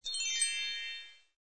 click01.mp3